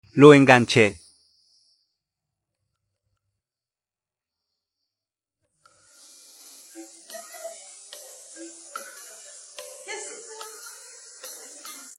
ভোকাল অংশ